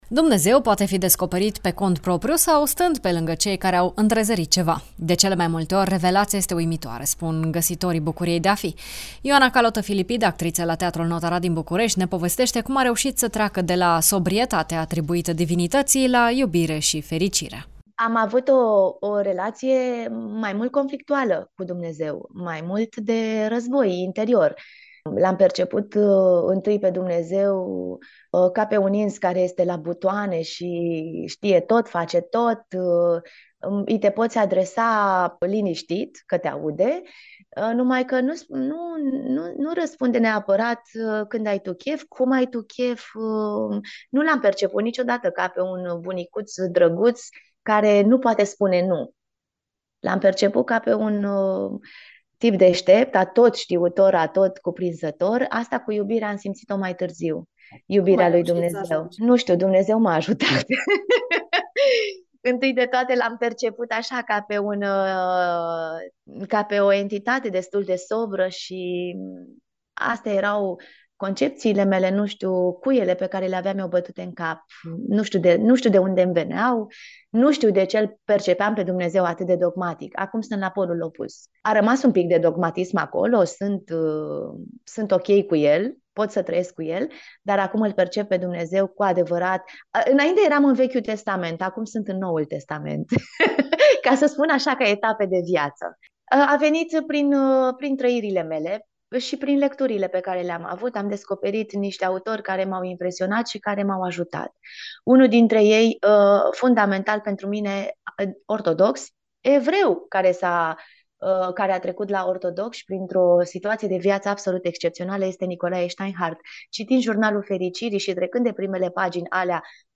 actriță: